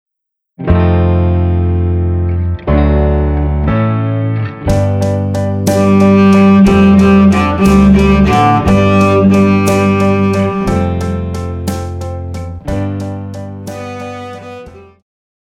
Pop
Cello
Band
Instrumental
Rock,Ballad
Only backing